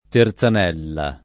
terzanella [ ter Z an $ lla ] s. f.